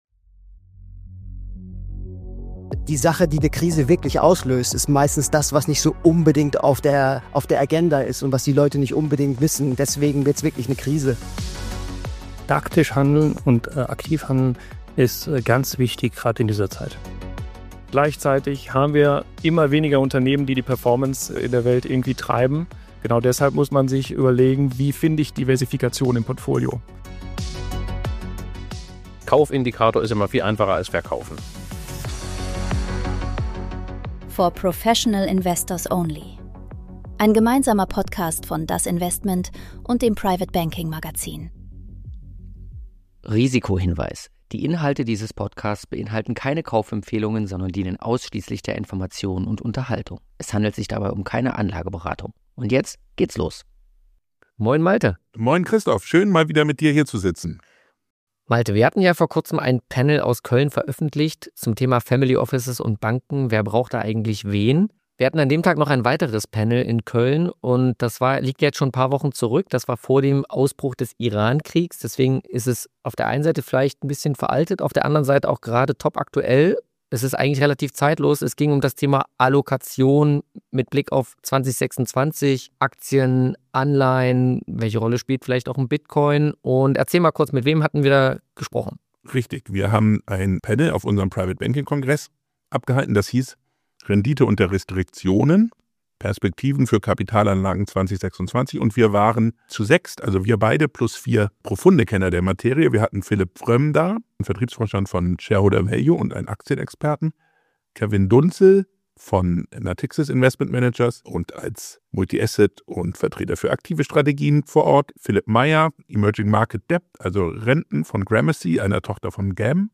Aufgezeichnet live beim private banking kongress in Köln diskutieren vier Experten die Allokationssichten für 2026 – mit Live-Abstimmungen aus dem Publikum. Es geht um Marktverengung, Scheindiversifikation, KI-Übertreibungen und die steile These, dass Benchmarks in den letzten 20 Jahren mehr Schaden angerichtet haben als Gutes getan.